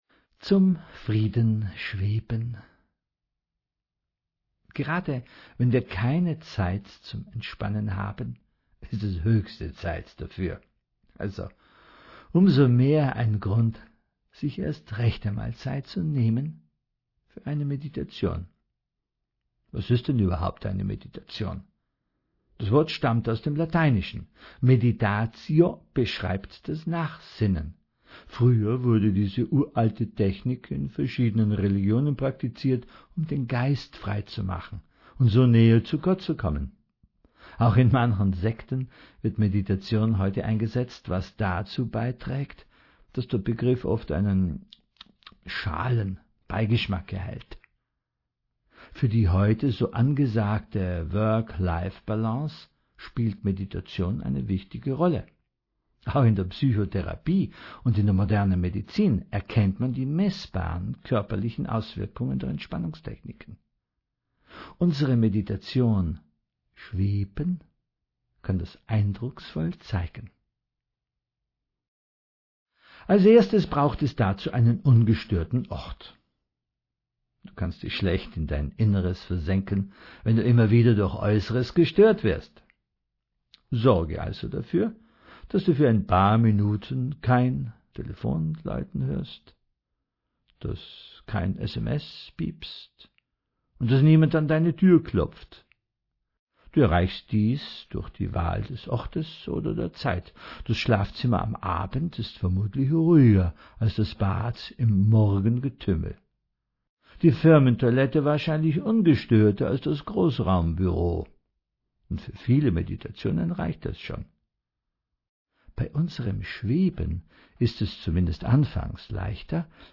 Erleben Sie innere Ruhe mit unserer Meditation, gesprochen direkt vom Autor.
Achtung: Alle Geräusche, die sie hören, auch die Pausen sind ein wichtiger Bestandteil der Meditation.
Am Ende führt Sie die Stimme wieder zurück in die Gegenwart.